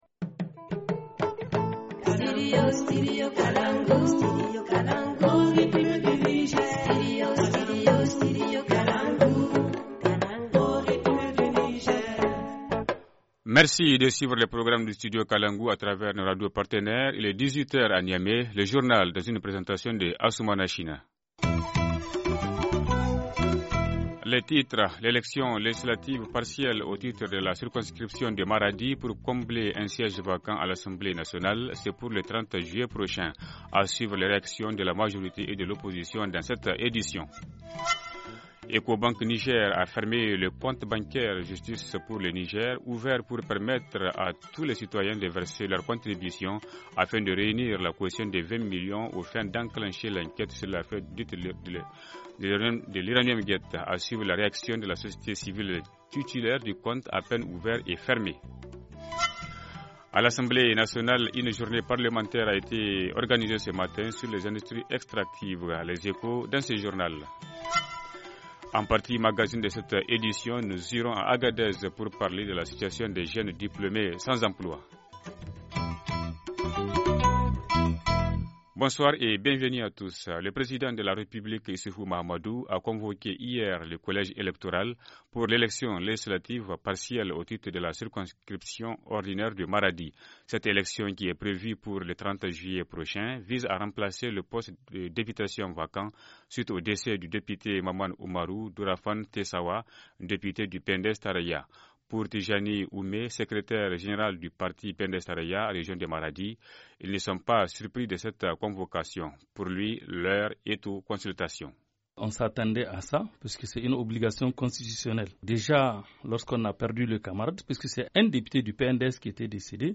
Journal du 6 mai 2017 - Studio Kalangou - Au rythme du Niger